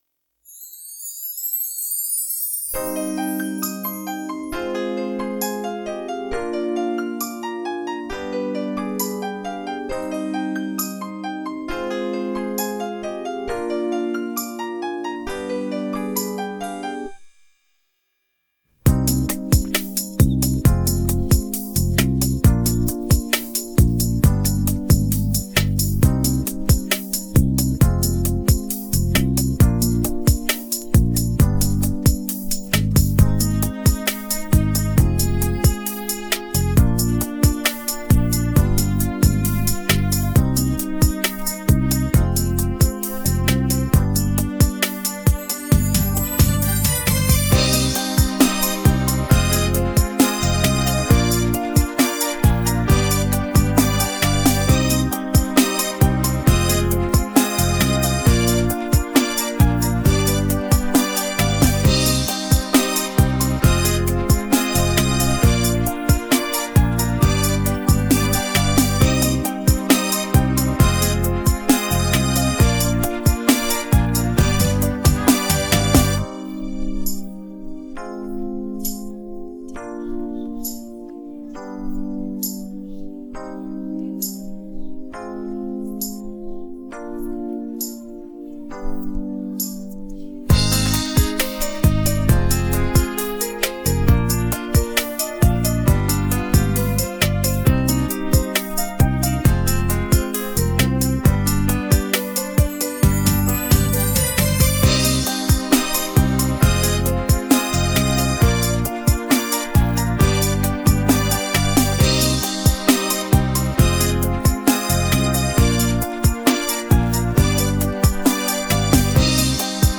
российская поп-группа